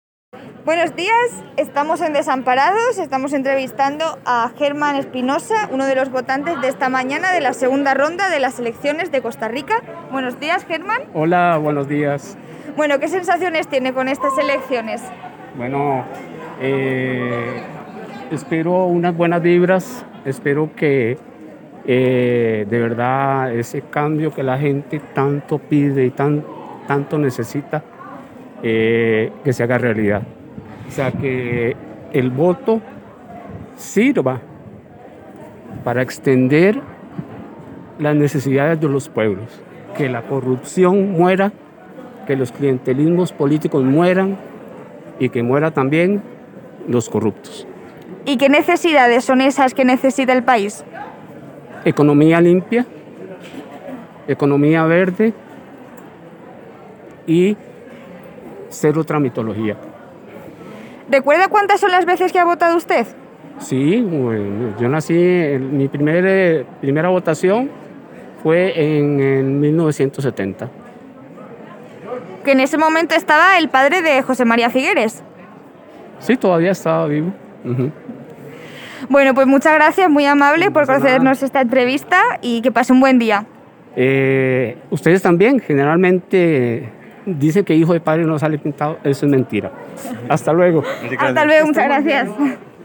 ENTREVISTA A UN VOTANTE DE LA JORNADA DEL DOMINGO